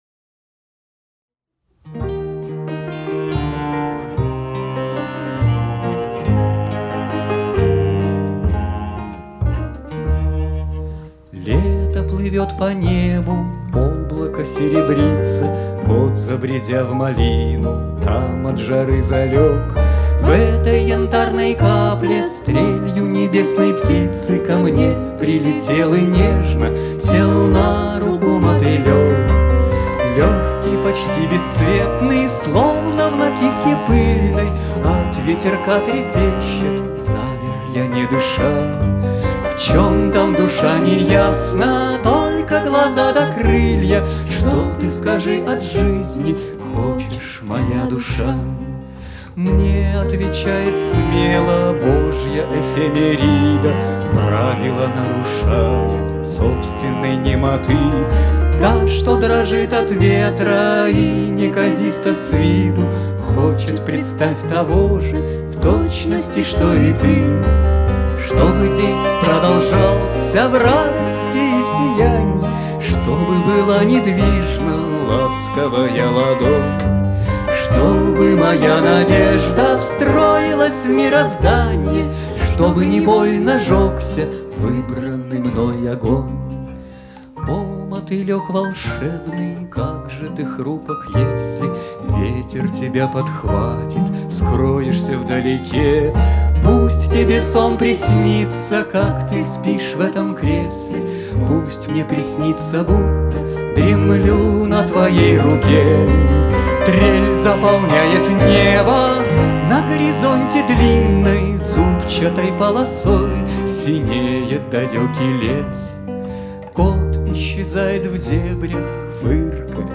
Новогодний концерт 27.12.2019
ударные
контрабас, бас-гитара
виолончель, вокал
клавишные, вокал
вокал и гитара